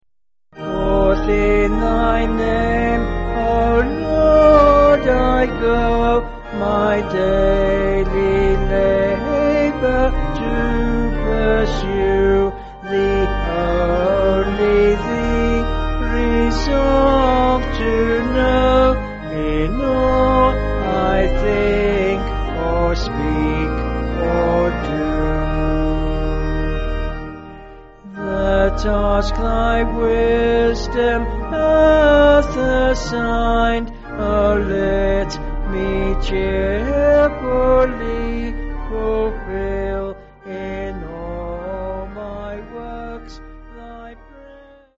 (BH)   6/Eb
Vocals and Organ